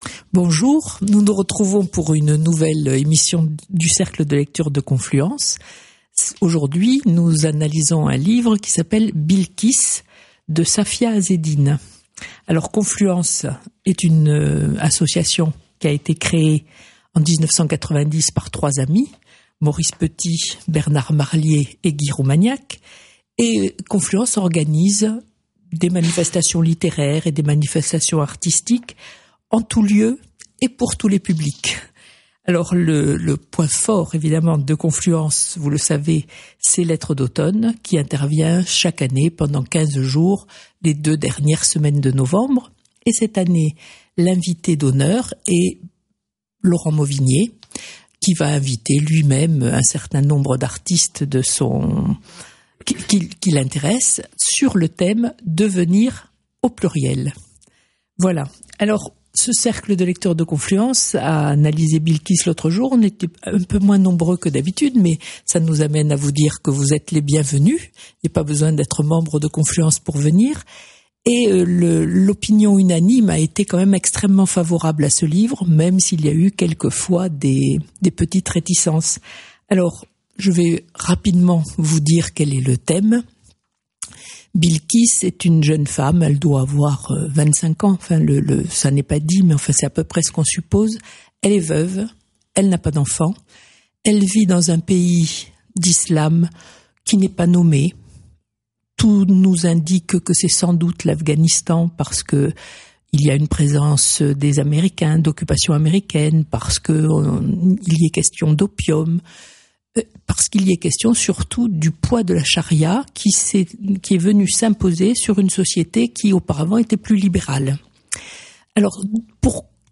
Il y a aussi des participants occasionnels..., CFM montauban